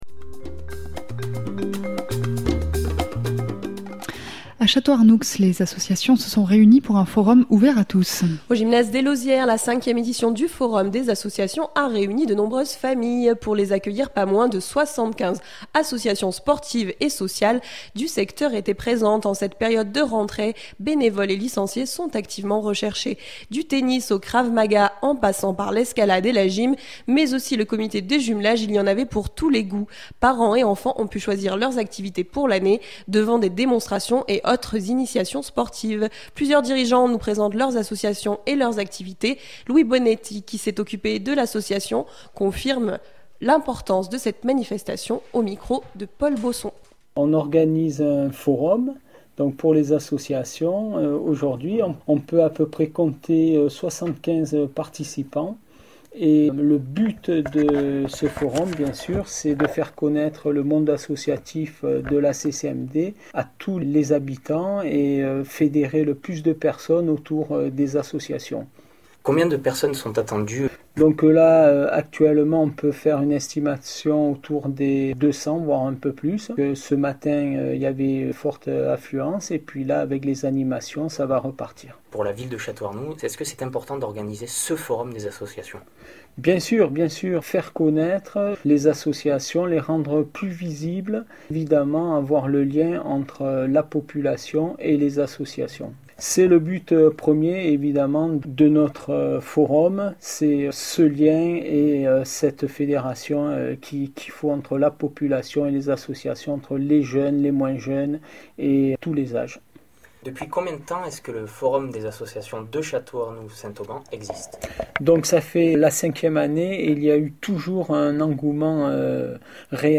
Au gymnase des Lauzières, la 5ème édition du forum des associations a réuni de nombreuses familles.
Plusieurs dirigeants nous présentent leurs associations et leurs activités.